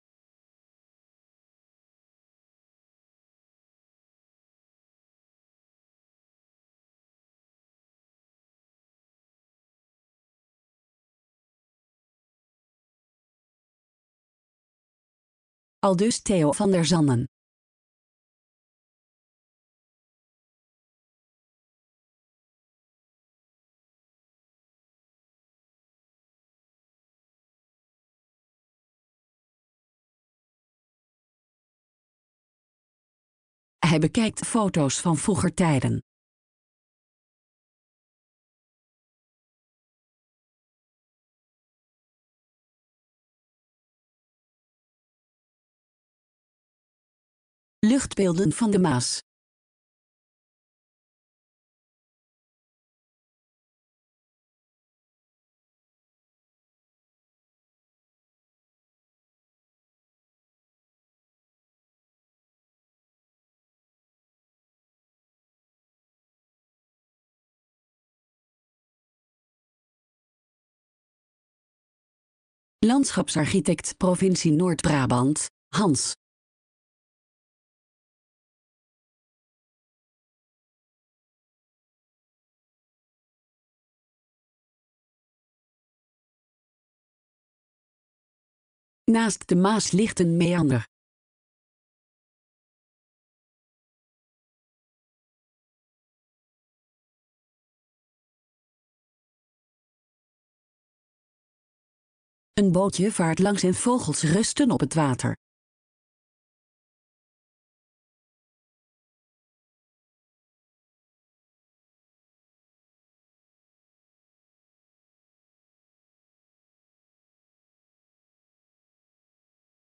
De film gaat over beheer en bescherming van de Maas en andere Brabantse wateren, en de uitdagingen om perioden met te veel en te weinig water op te vangen. Verschillende betrokkenen, onder wie een binnenvisser, een landschapsarchitect en de droogtecoördinator van een waterschap, vertellen over hun werk en hun liefde voor de wateren van Brabant.